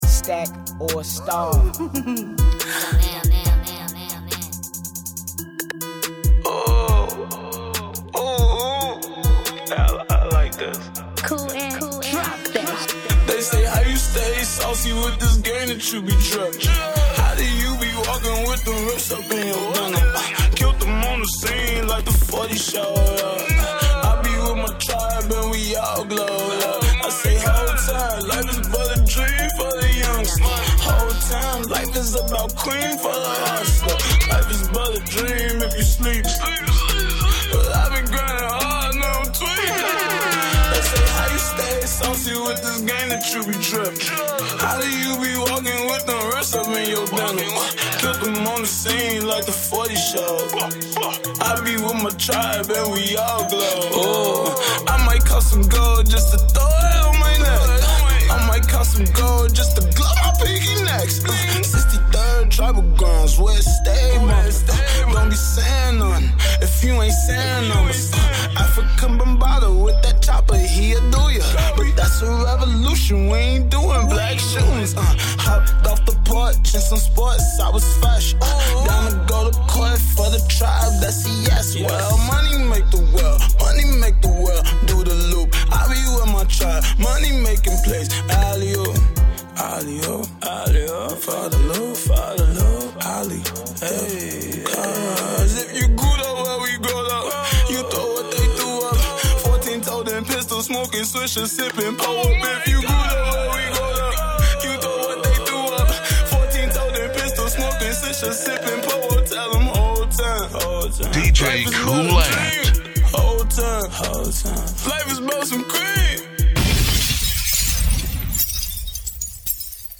Hiphop
A new single guaranteed to add bounce to your Dj playlist.